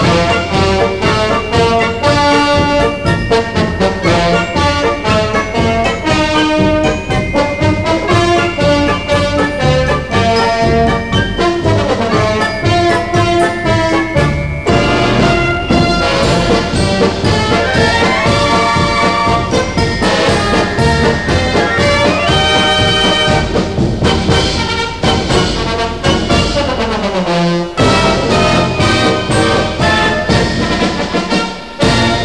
Found it! it is called "Gridiron Heroes". this version doesn't have vocals, but at Ford Field they actually have a live band that plays it live after every score, and a vocalist who sings the words.
Detroit Lions Fightsong.wav